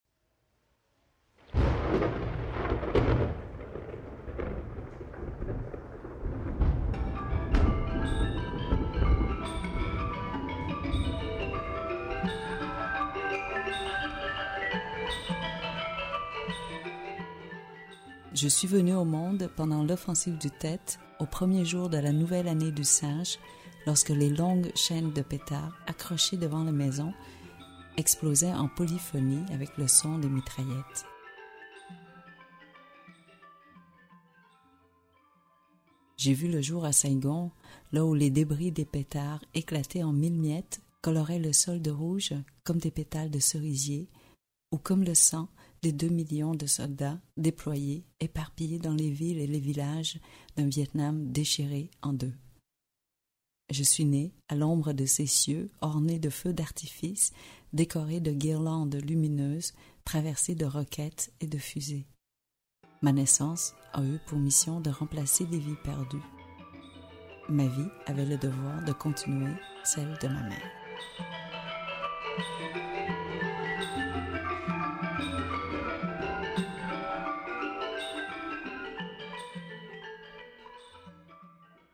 Click for an excerpt - Ru de Kim THUY
Lu par Kim THUY Durée : 2 heures 48 min × Guide des formats Les livres numériques peuvent être téléchargés depuis l'ebookstore Numilog ou directement depuis une tablette ou smartphone.